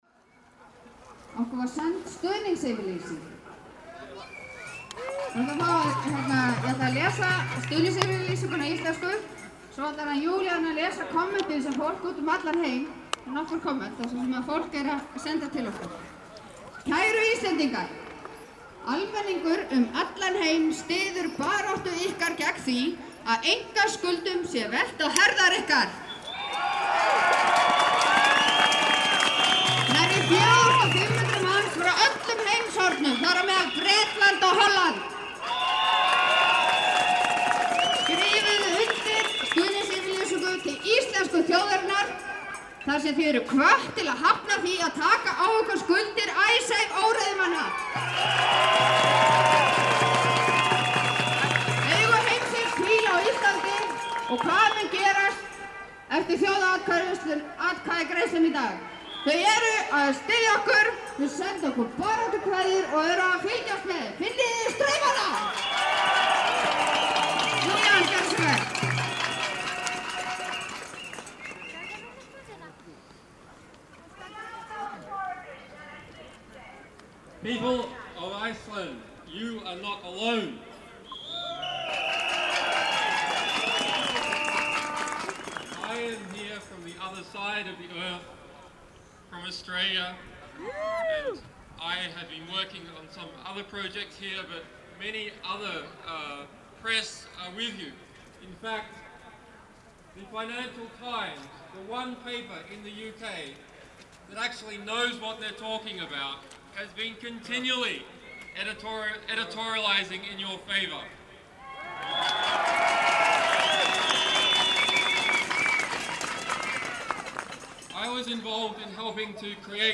Hljóðmyndin sem hér má heyra er af stuðningsyfirlýsingum sem borist hafa víða utan úr heimi. Tekið var upp á Olympus LS10 og MMaudio Binaural hljóðnema í 24bit/98Khz. Umtalsverður vindur var á Austurvelli því þurfti að nota lágtíðnisíu (82Hz). Upptakan er því fremur grunn.